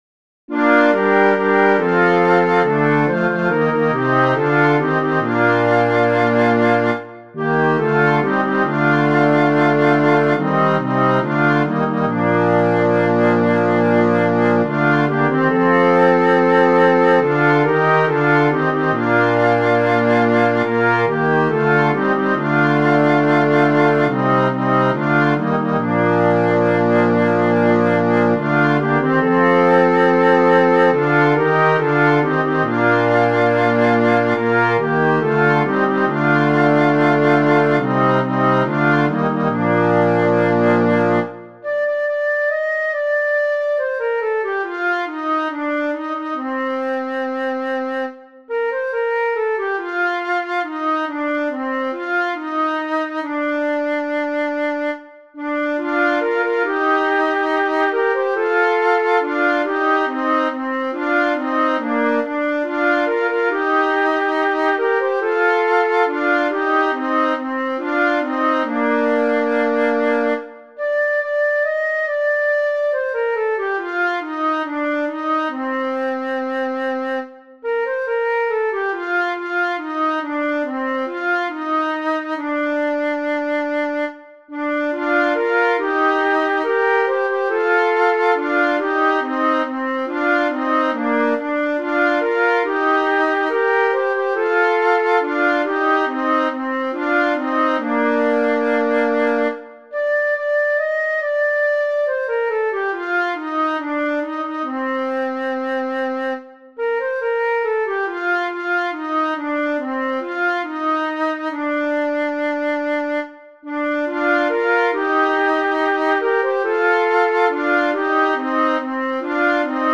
• Catégorie : Chants de Carême